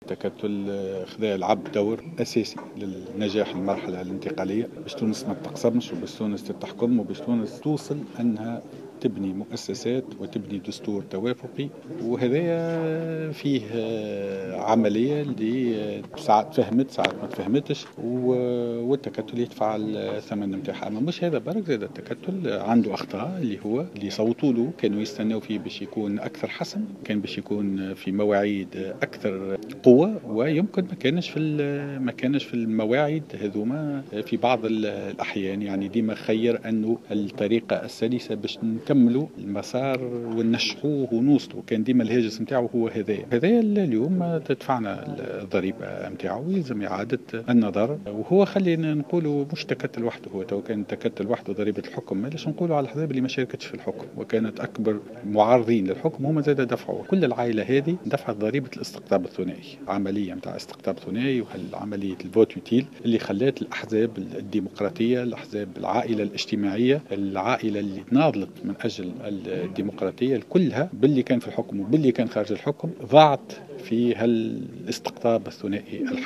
و قال الفخفاخ على هامش ندوة لمنتدى الجاحظ " من صوتوا للتكتل كانوا ينتظروا منه مواقف أكثر حزم ولكنه خّير الطريقة السلسة لاستكمال المسار وقد دفعنا ضريبة ذلك" .وأضاف أن العائلة الديمقراطية دفعت ضريبة الاستقطاب الثائي والتصويت المفيد.